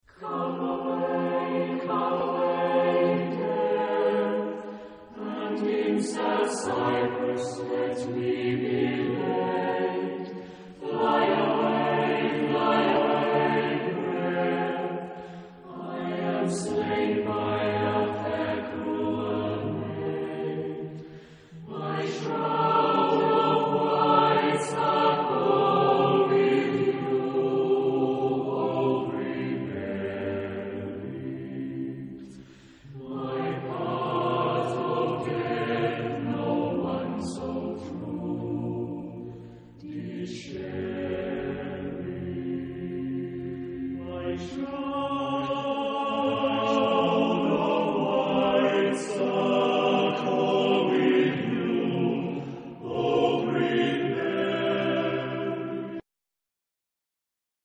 Genre-Stil-Form: weltlich ; Chor
Charakter des Stückes: Chromatik ; geheimnisvoll ; langsam
Chorgattung: SSAATBB (7 gemischter Chor Stimmen)
Tonart(en): f-moll ; ges-moll